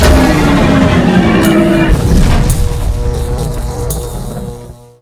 CosmicRageSounds / ogg / general / combat / enemy / droid / bigdie3.ogg
bigdie3.ogg